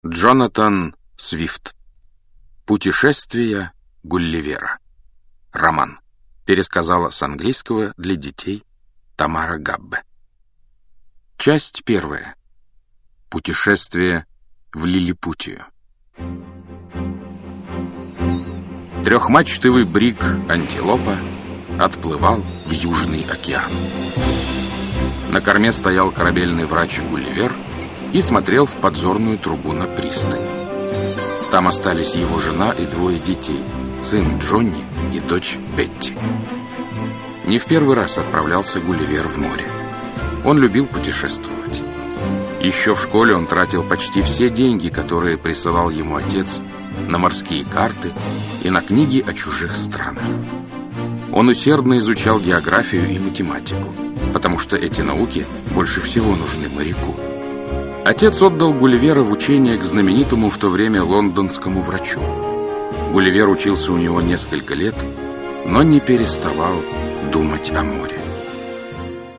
Аудиокнига Путешествия Гулливера | Библиотека аудиокниг
Aудиокнига Путешествия Гулливера Автор Джонатан Свифт Читает аудиокнигу Александр Клюквин.